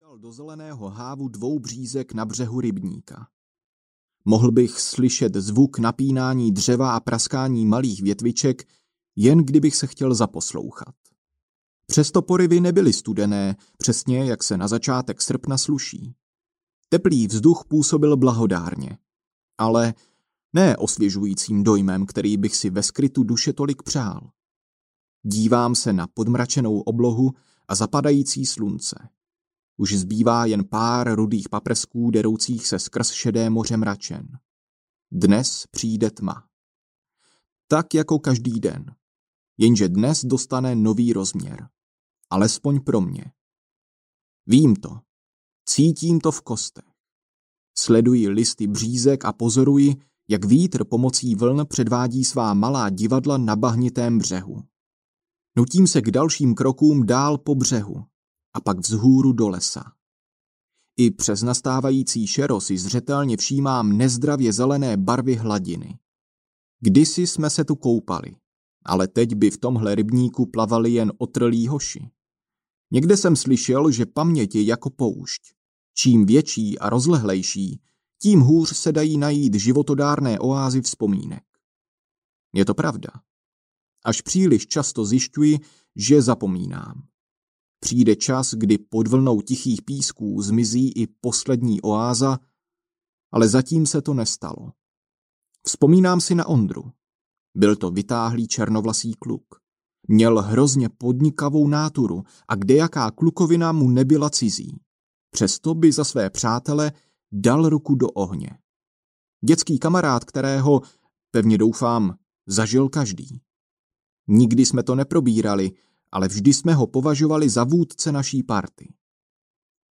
Pár zbytků pro krysy audiokniha
Ukázka z knihy